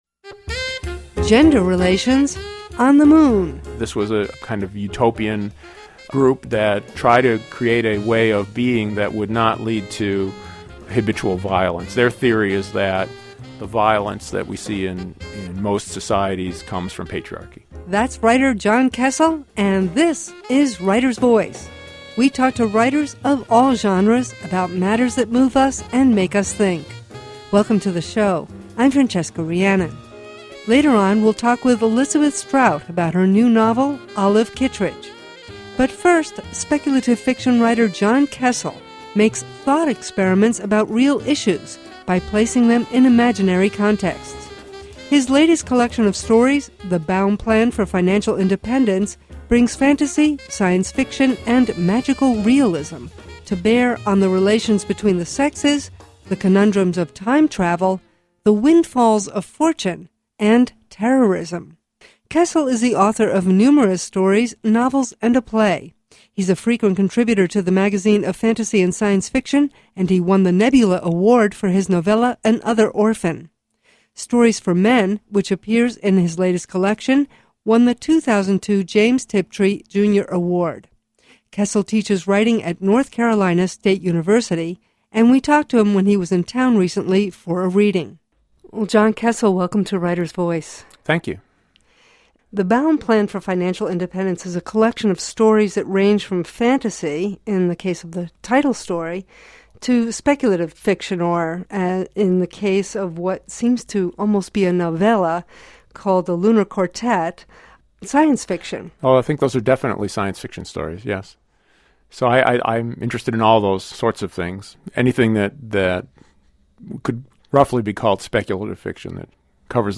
Writer’s Voice — in depth conversation with writers of all genres, on the air since 2004.